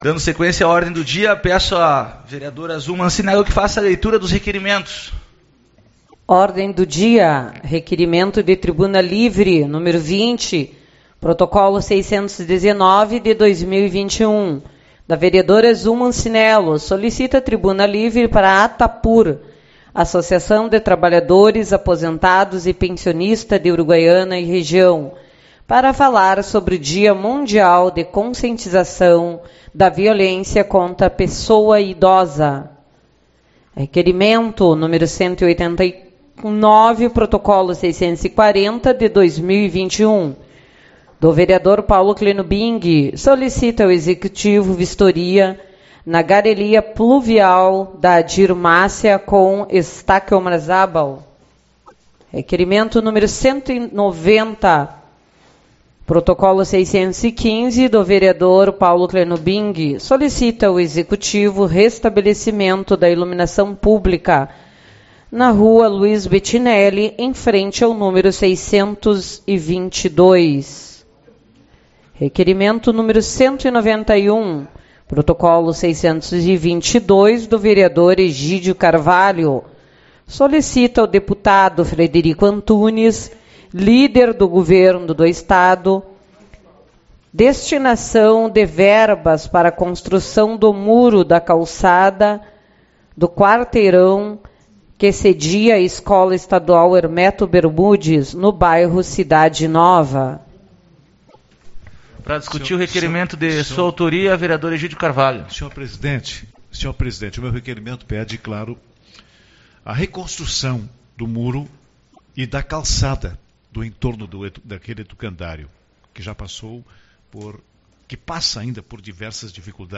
15/06 - Reunião Ordinária